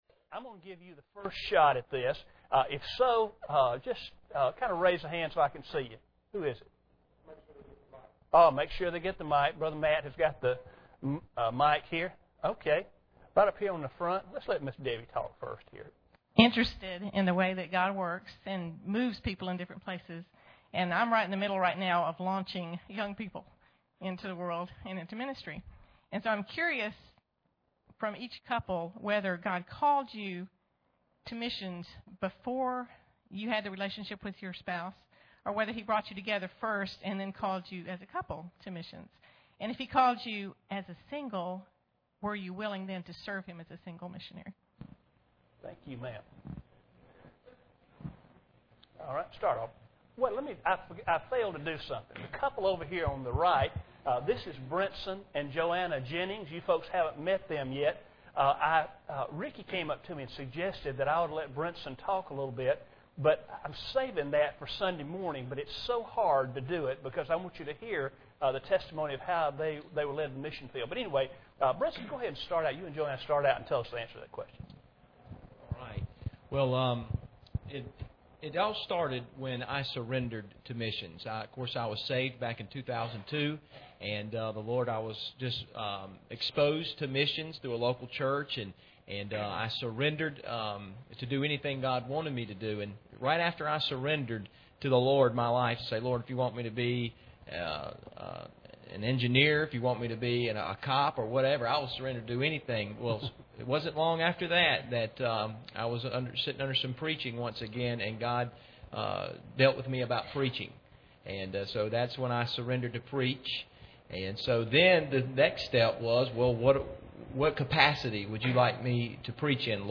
A Conversation with the Missionaries
Preacher: 2010 Missionary Panel | Series: 2010 Missions Conference
MissionaryForum.mp3